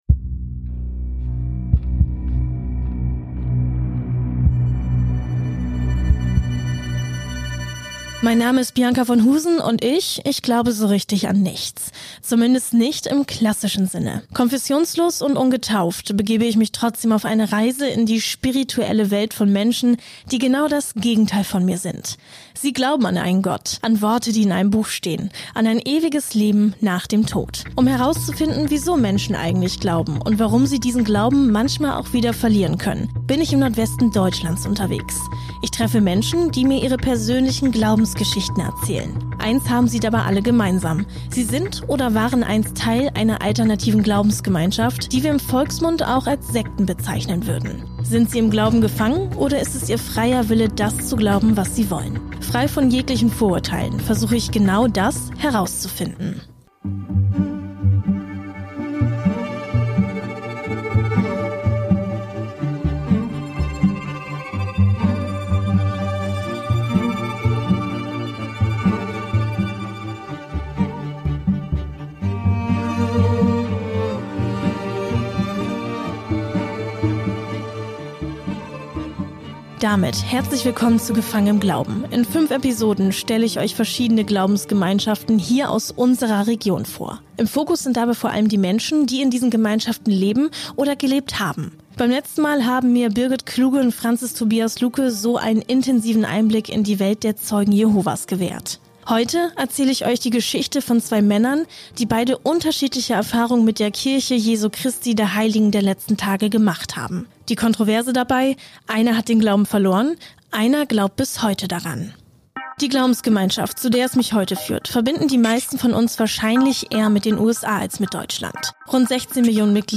Die beiden Männer teilen mit mir ihre persönlichen Glaubensgeschichten.